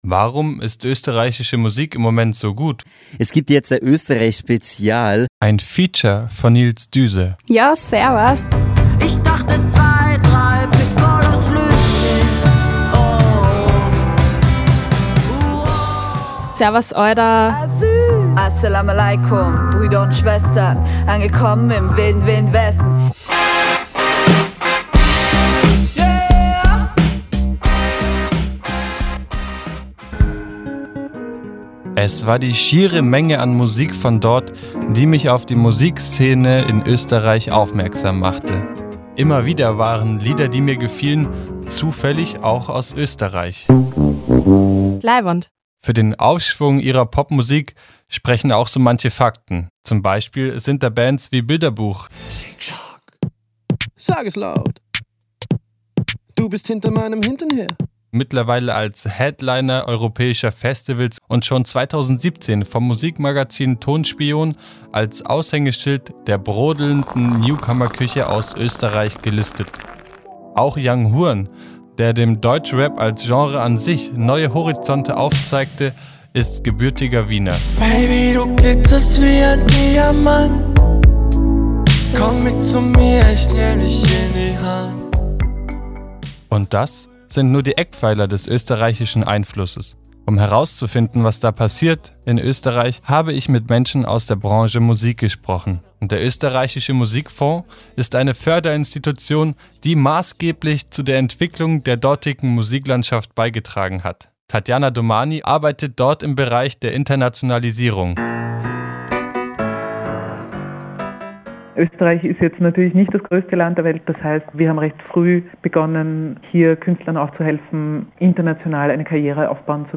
Ein Feature zum neuen Schwung Ösi-Pop